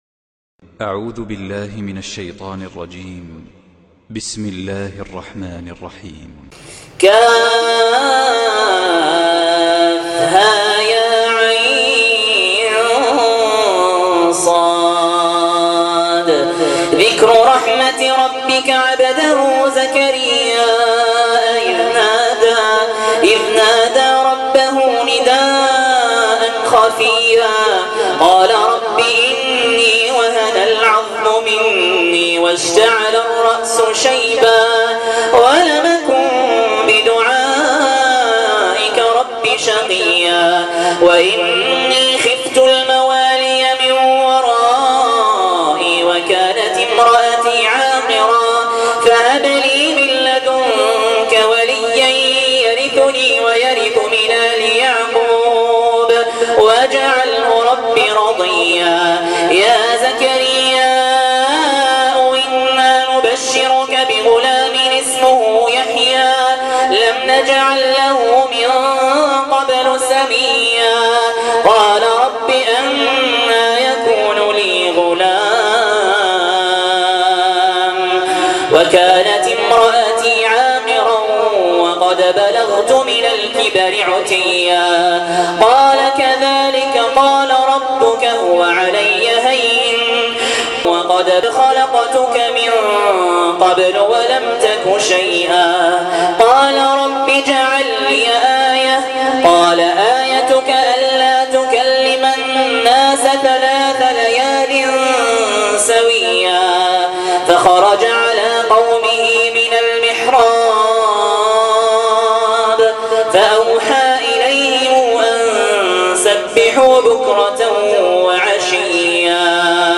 تلاوات خاشعة تلاوات من الصلوات الجهرية